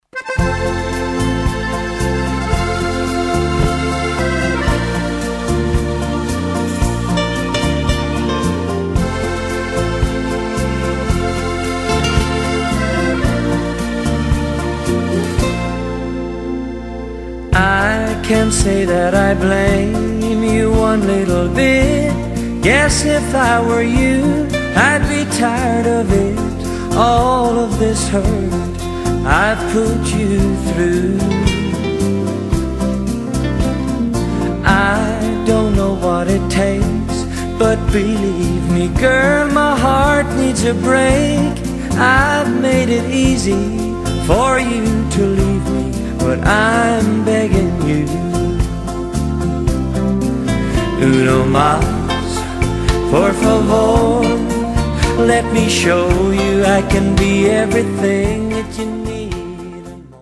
(20 Latin 3-Step, Rumba & Slow Dance Favorites)<